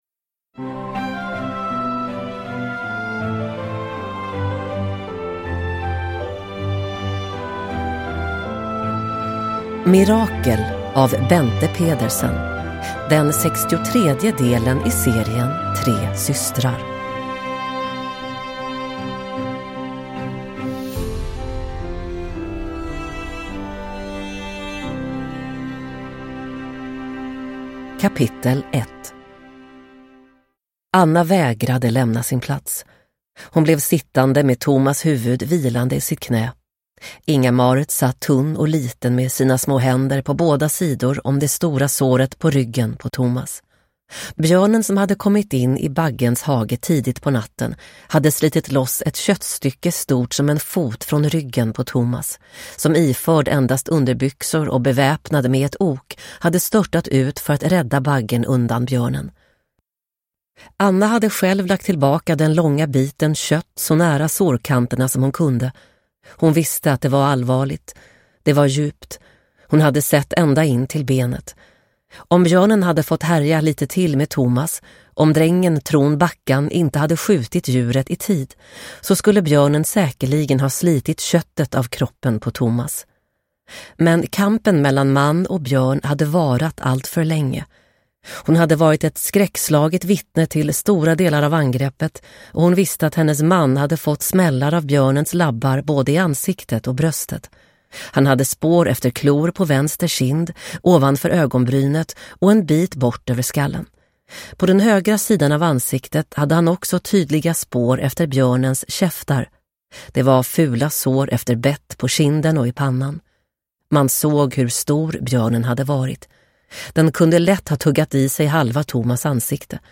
Mirakel – Ljudbok – Laddas ner